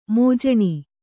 ♪ mōjaṇi